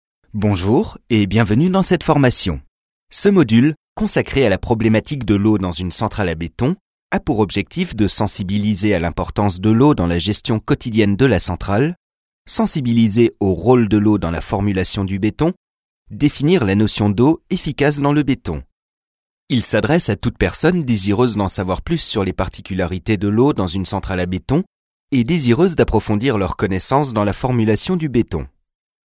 Une voix : médium, au grain atypique, au timbre chaleureux, malléable et adaptable à tous vos projets Un équipement professionnel : microphone Neumann TLM 103, Préampli SPL GoldMike MK II, cabine de prise de son de 10 m3 intégralement traitée acoustiquement.
Le timbre de ma voix est médium, jeune.
Sprechprobe: eLearning (Muttersprache):
french voice actor, medium voice